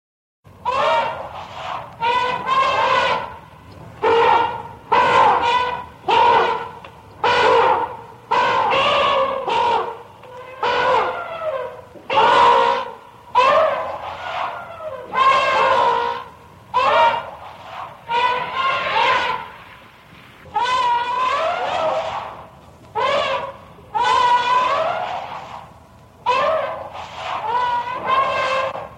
elephant-sounds.mp3